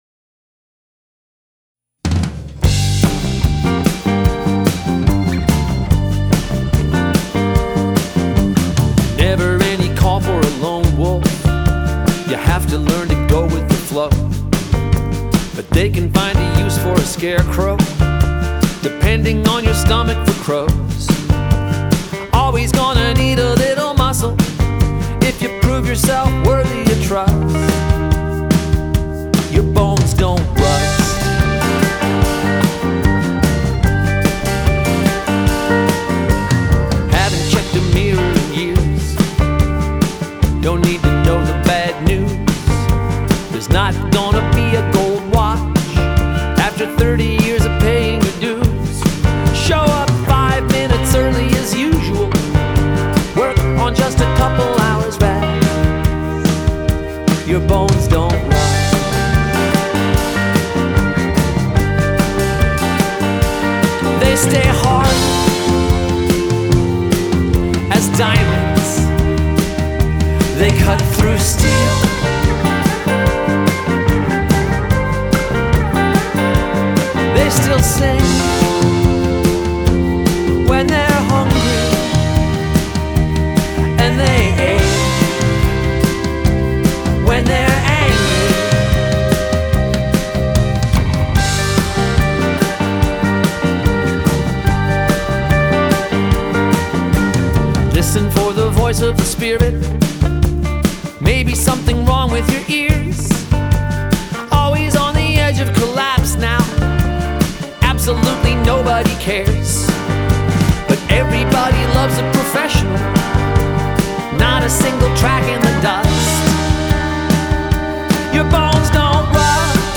Genre : Alternative